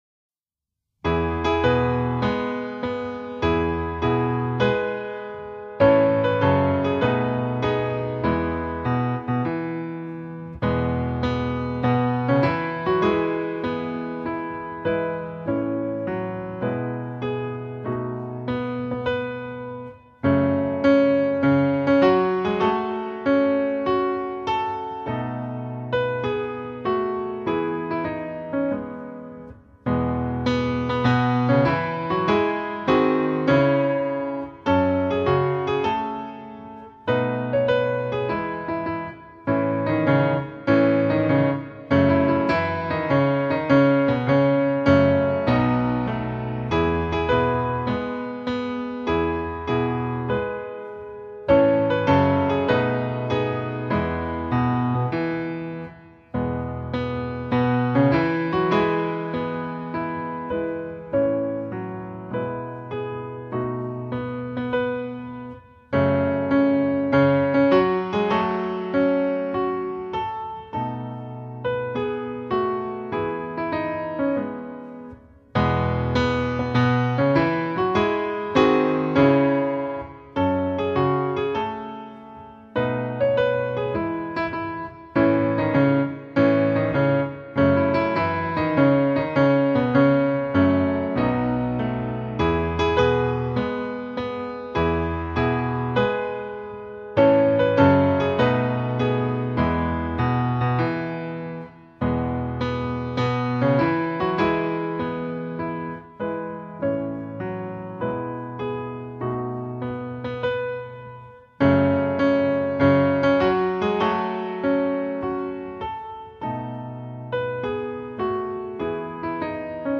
(ピアノ伴奏)